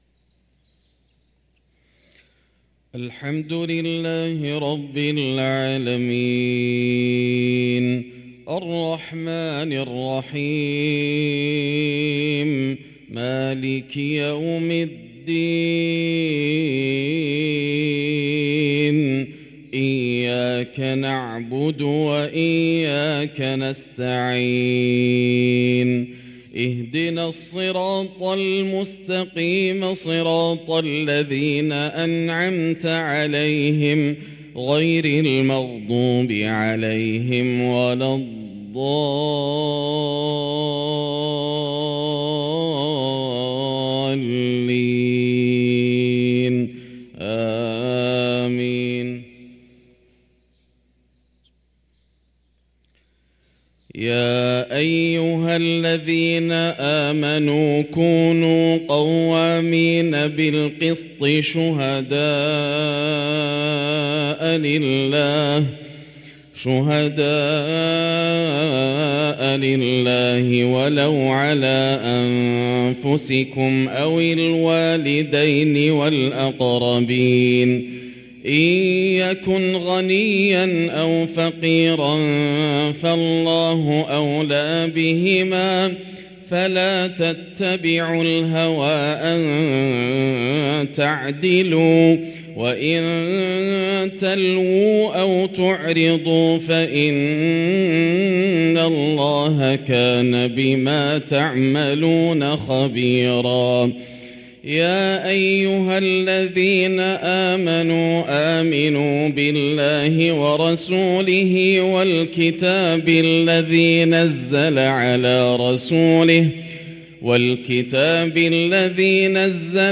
صلاة الفجر للقارئ ياسر الدوسري 9 شعبان 1444 هـ
تِلَاوَات الْحَرَمَيْن .